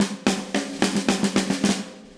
AM_MiliSnareC_110-01.wav